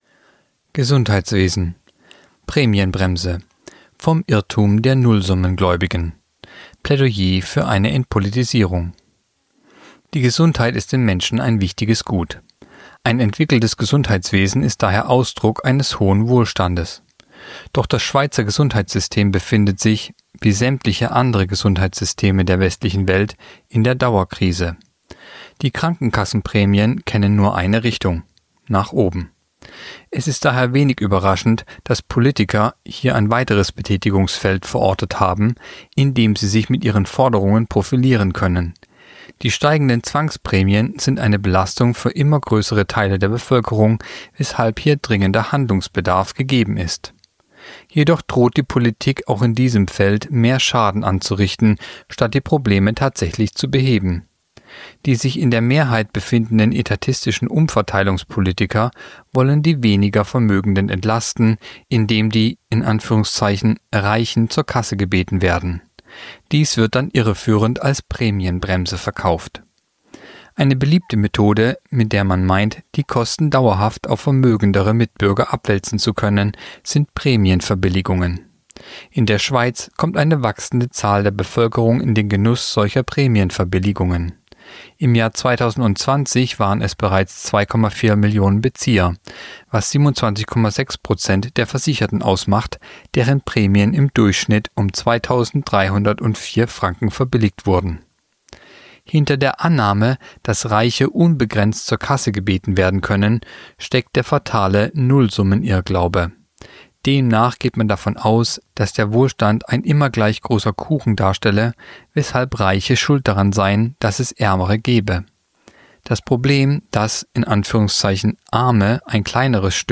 Kolumne der Woche (Radio)Prämienbremse: Vom Irrtum der Nullsummengläubigen